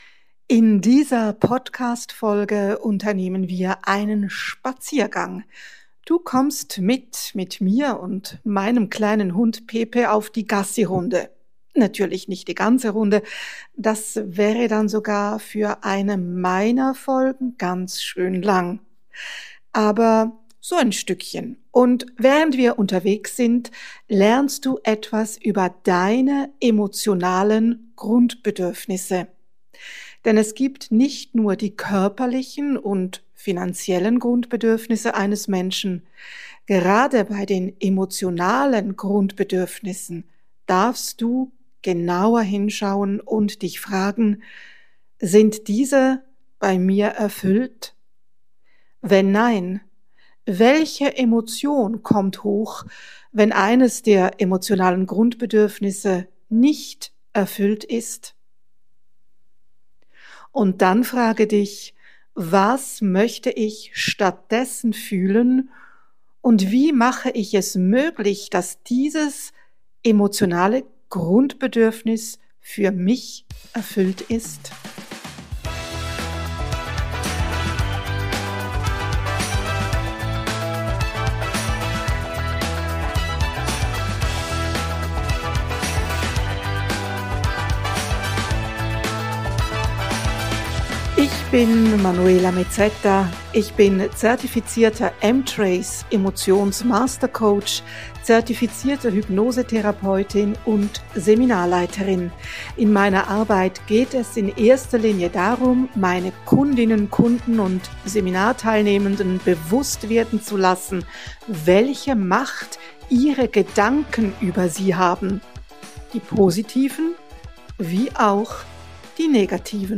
In dieser Podcastfolge nehme ich dich auf einen Spaziergang mit.
Während wir die frische Morgenluft atmen erfährst Du etwas über Deine emotionalen Grundbedürfnisse.